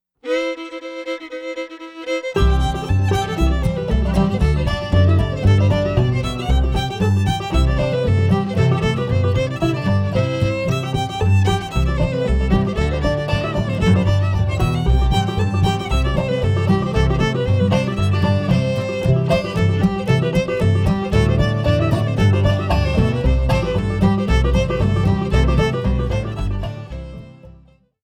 Old Time Music of SW Pennsylvania
fiddle
banjo, fife, accordion
guitar
upright bass Between 1928 and 1963